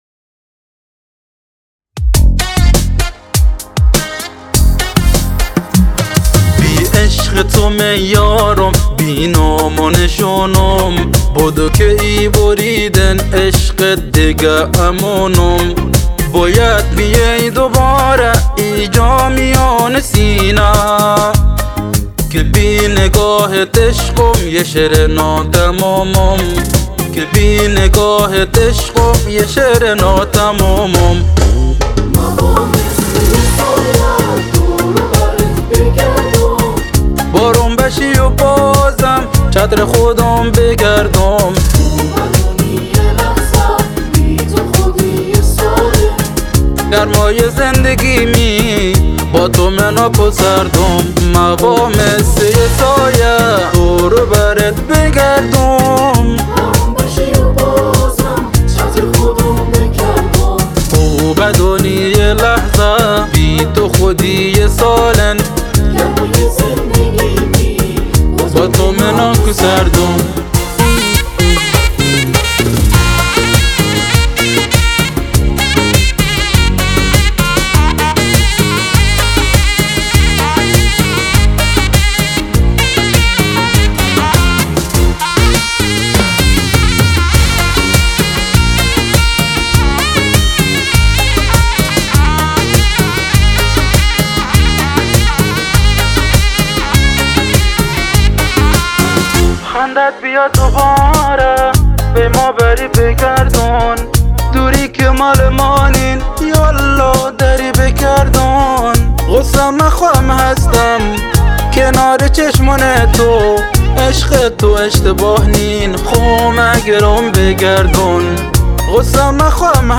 گیتار باس
سُرنا
بک‌وکال